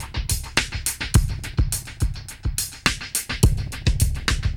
Index of /musicradar/dub-drums-samples/105bpm
Db_DrumsA_KitEcho_105_01.wav